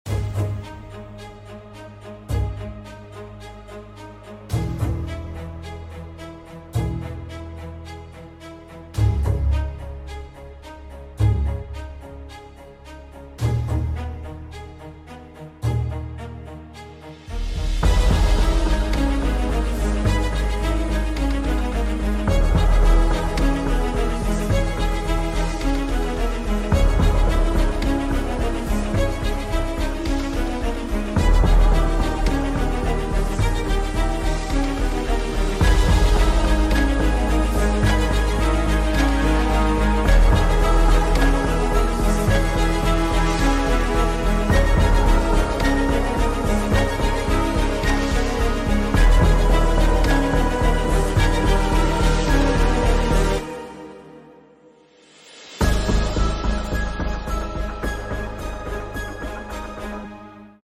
An original main title theme